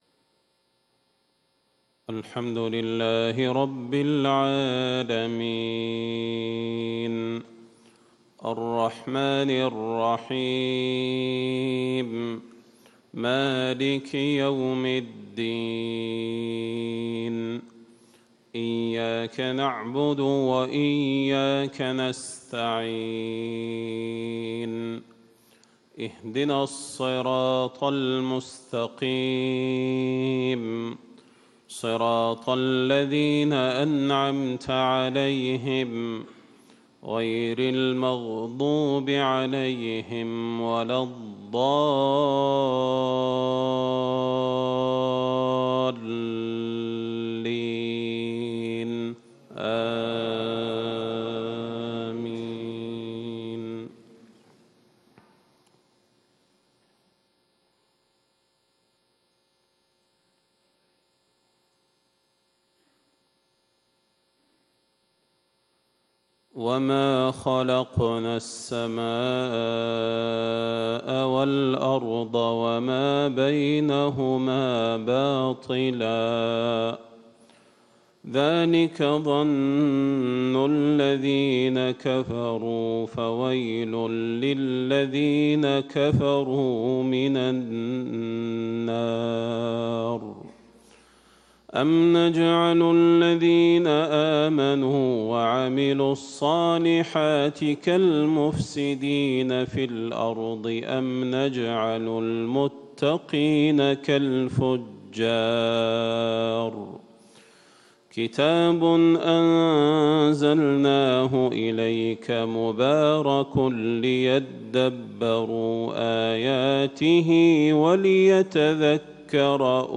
صلاة الفجر 12 ذو القعدة 1437هـ من سورة ص 27-68 > 1437 🕌 > الفروض - تلاوات الحرمين